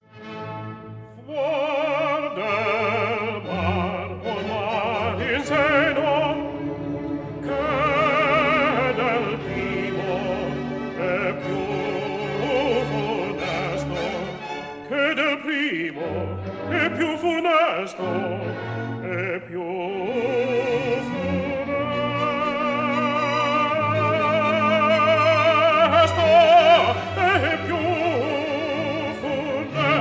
tenor
Recorded in Abbey Road Studio No. 1, London